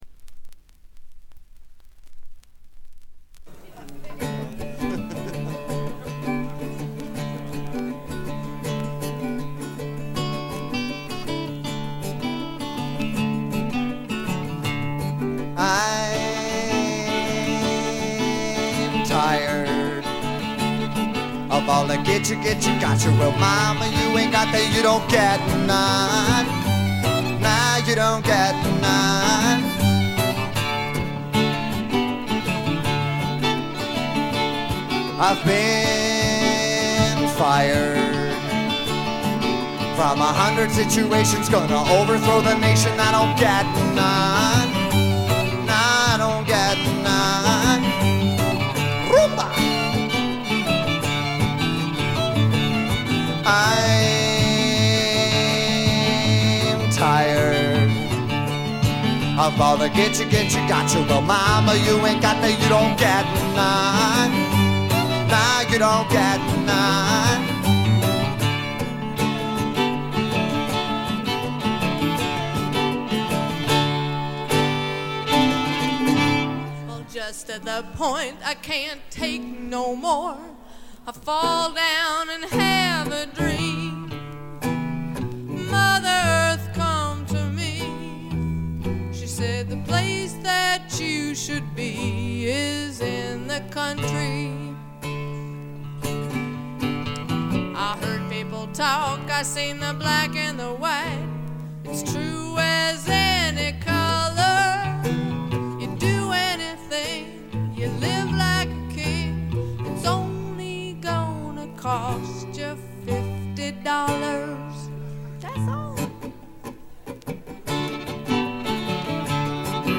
バックグラウンドノイズ、チリプチ多め大きめ。
カナダを代表するヒッピー・フォークの3人組で大学でのライヴ録音、自主制作だと思います。
ギター、ヴァイオリン、ダルシマー、フルート等によるアコースティックなフォークですが、とてもへんてこな感覚がつき纏います。
試聴曲は現品からの取り込み音源です。